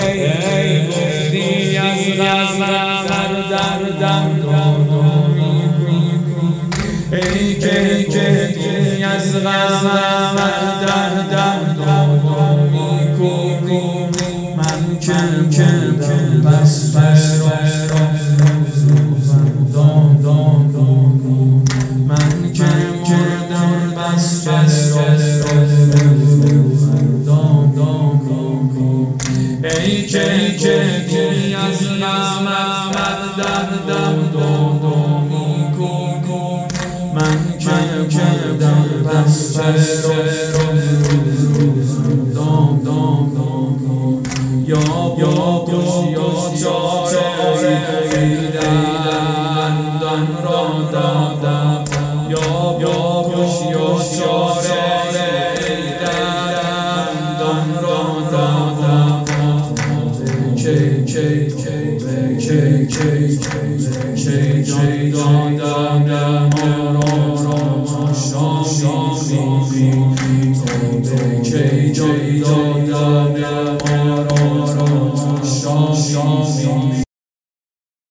واحد،ای که گفتی از غمت دردم مداوا میکنی